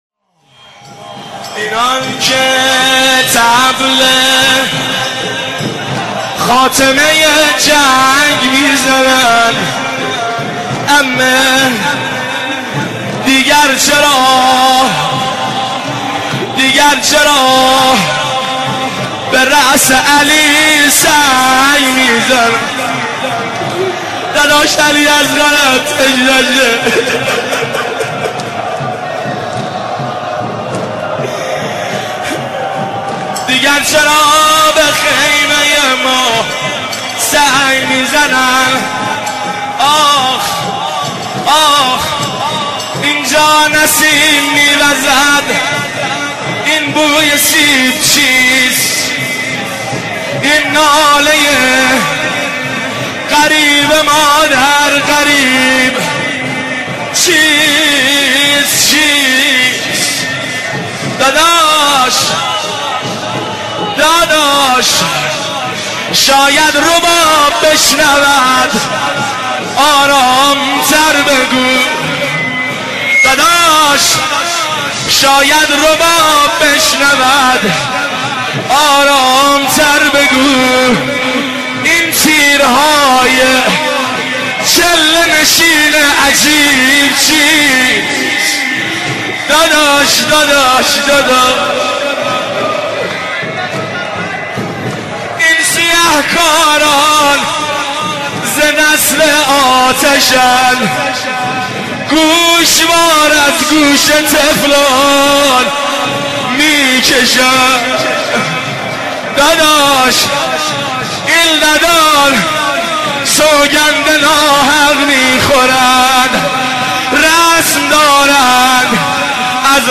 روضه خوانی شب عاشورای محرم الحرام 1390